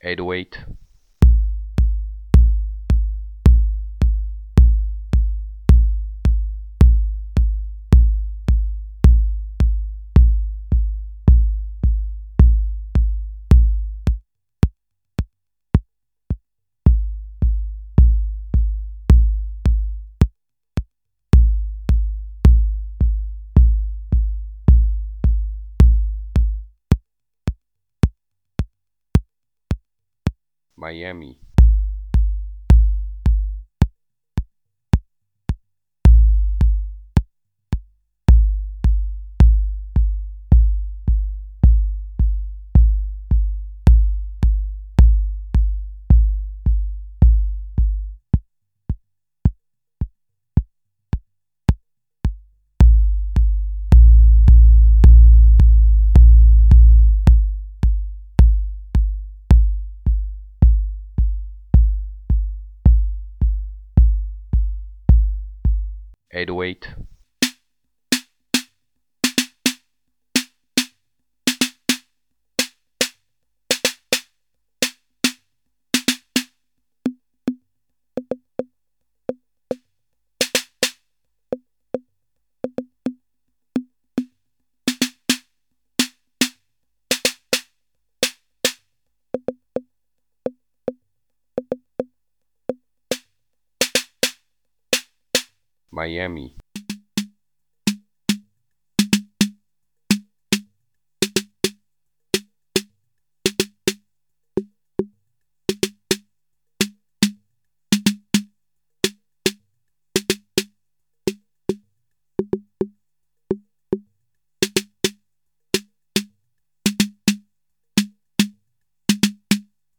another TR808 on this file, same thing
The frequency is a tiny bit lower than on my 808 and it has a crazy long decay.
Snaredrum : my 808 Snare has a higher basic pitch and more noise (due to the internal TM 4 pot setting which also affects the toms) .
Maracas: the miami „shaker“ sounds like a „scratch“, not very smooth (could be due to pot settings) – clear advantage for 808 here
Cymbals : lower basic frequency and longer decay on my 808, no important difference for me